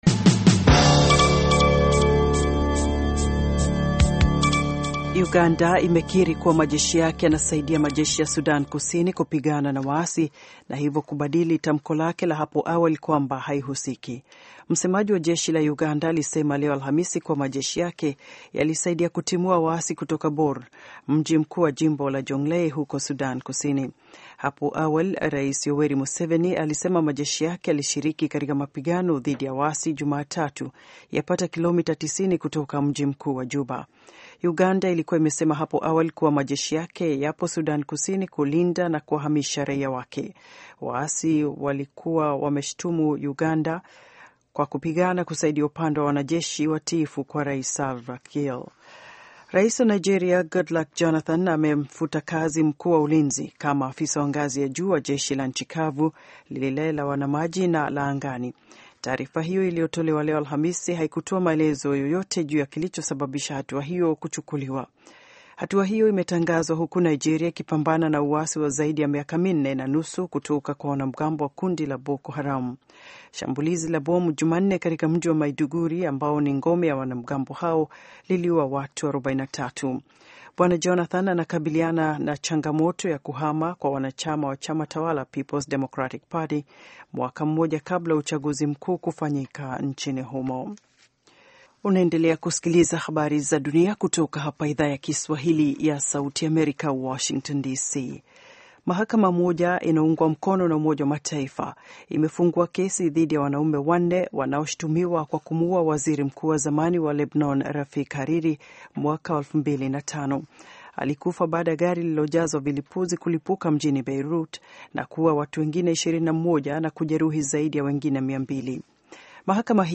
Taarifa ya Habari VOA Swahili - 6:04